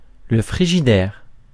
Ääntäminen
France: IPA: [lə fʁi.ʒi.dɛʁ]